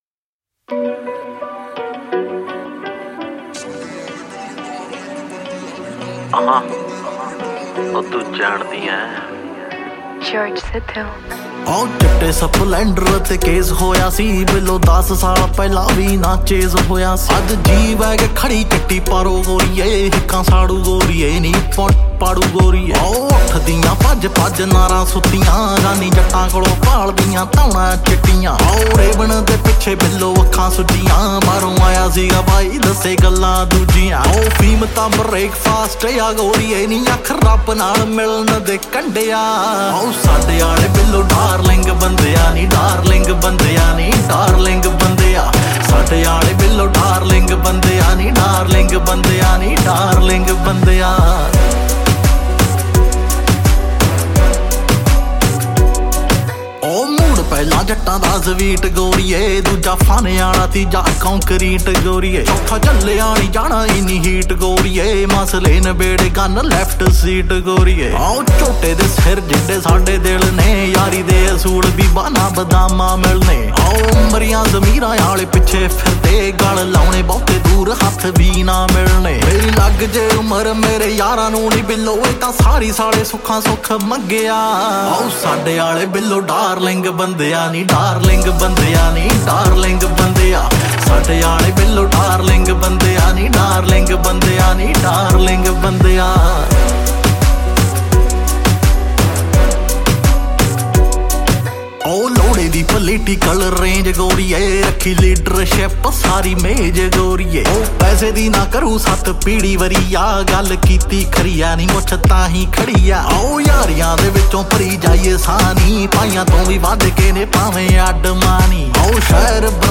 old Punjabi song